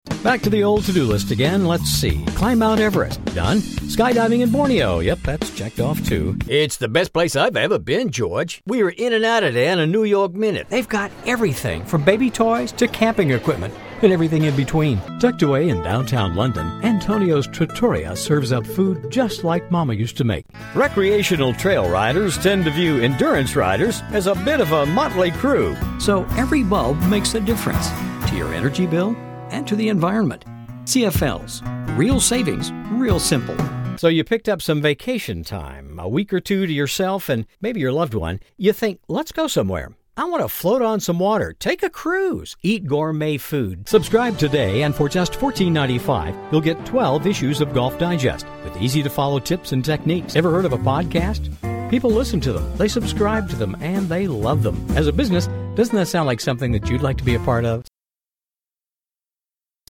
American male voice talent offering voice files for corporate & media clients. Professional studio.
Sprechprobe: Werbung (Muttersprache):